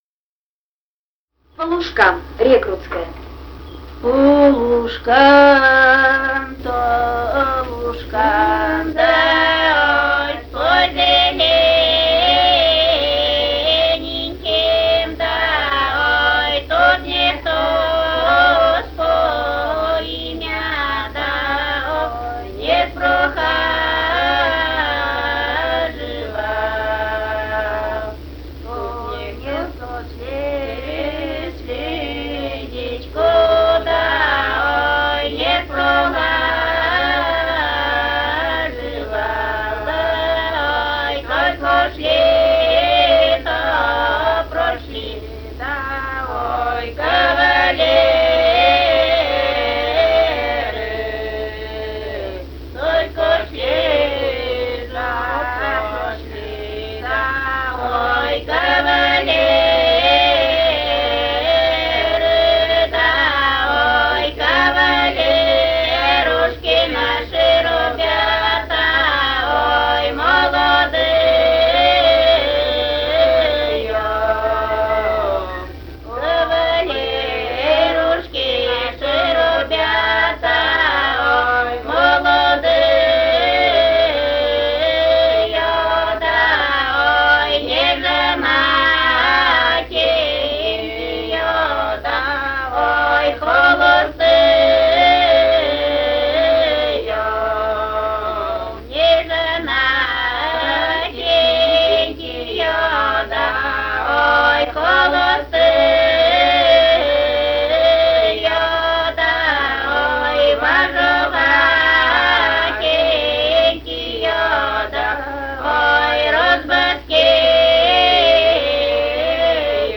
Этномузыкологические исследования и полевые материалы
«По лужкам-то, лужкам» (хороводная).
Пермский край, д. Монастырка Осинского района, 1968 г. И1075-06